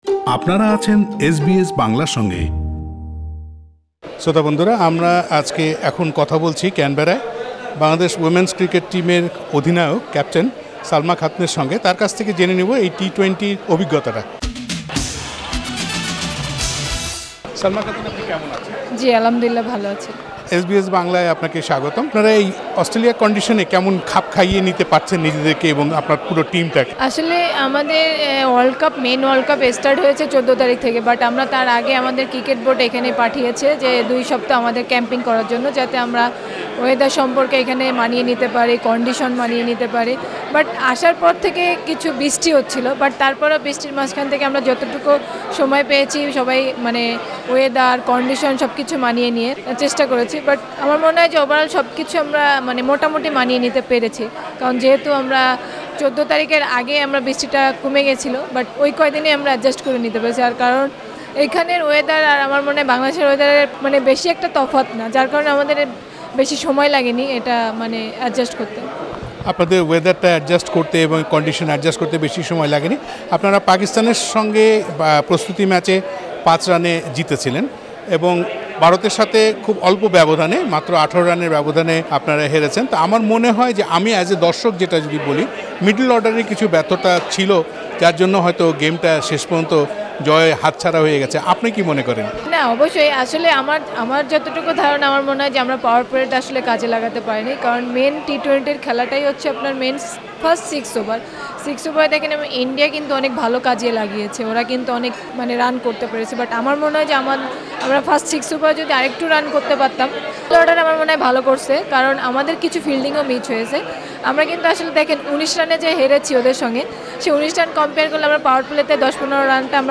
গত ২৬ ফেব্রুয়ারী বাংলাদেশ হাই কমিশন ক্যানবেরা নারী ক্রিকেট টীম কে সংবর্ধনা দেয় ক্যানবেরার একটি রেস্টুরেন্টে ।
সেখানেই ক্রিকেট নিয়ে কথা হয় নারী দলের ক্যাপ্টেন সালমা খাতুন এর সঙ্গে। তিনি বাংলাদেশের নারী ক্রিকেট নিয়ে নানান কথা বলেন এস বি এস বাংলাকে। সালমা খাতুন এর সাক্ষাৎকারটি শুনতে উপরের লিংকটিতে ক্লিক করুন।